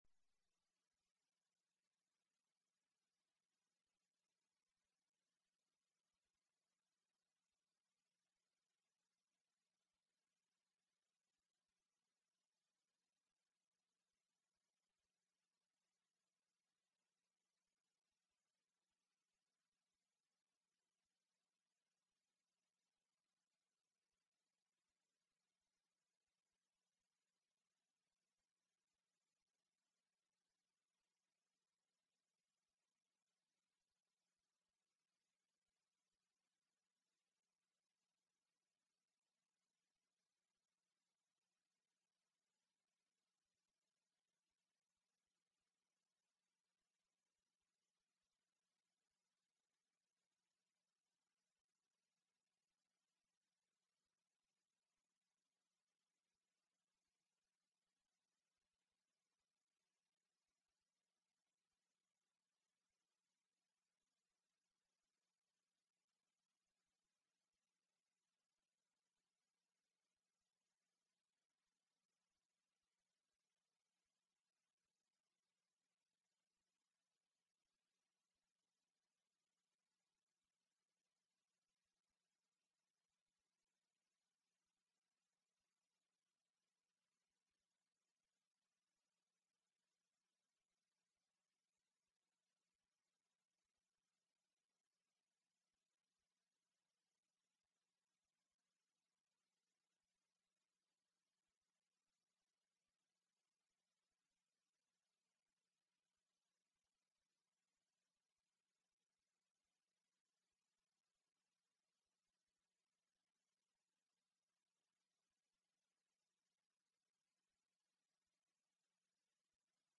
Recorded: Thursday, January 15, 2026 at Insight Meditation Center